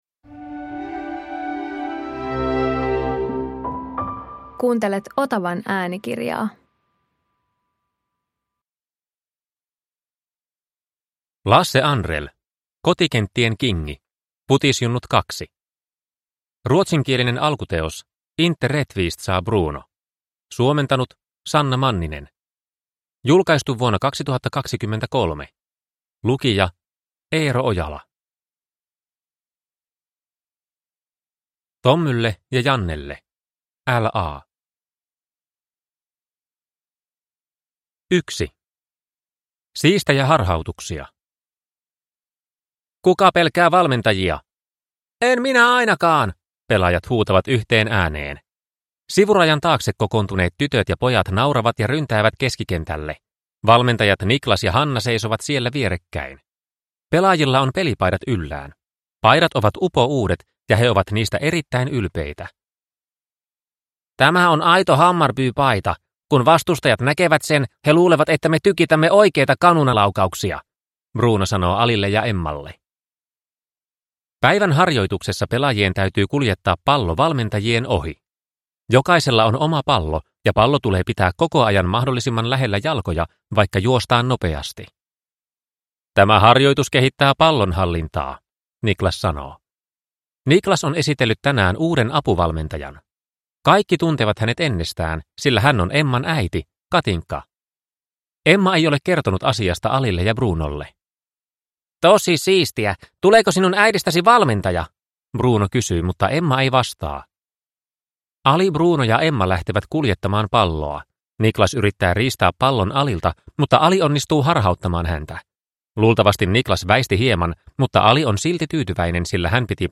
Kotikenttien kingi – Ljudbok – Laddas ner